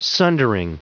Prononciation audio / Fichier audio de SUNDERING en anglais
Prononciation du mot sundering en anglais (fichier audio)